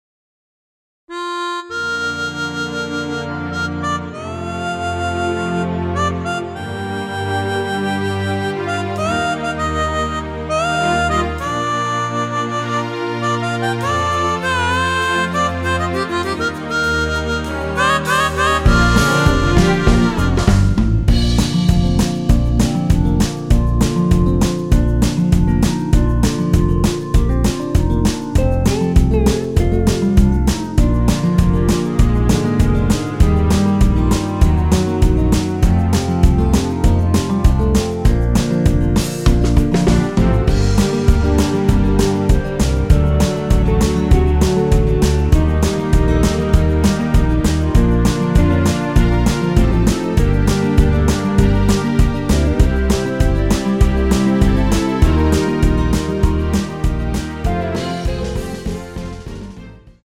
전주가 길어서 8마디로 편곡 하였으며
원키에서(-2)내린 (1절+후렴)으로 진행되는 멜로디 포함된 MR입니다.
Bb
앞부분30초, 뒷부분30초씩 편집해서 올려 드리고 있습니다.
중간에 음이 끈어지고 다시 나오는 이유는